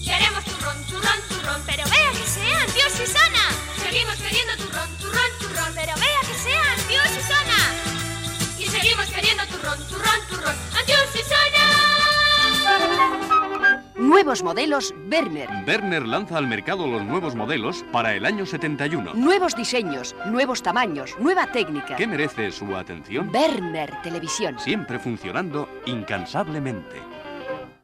Gènere radiofònic Publicitat Anunciant Turrón Antiu Xixona, Verner Televisión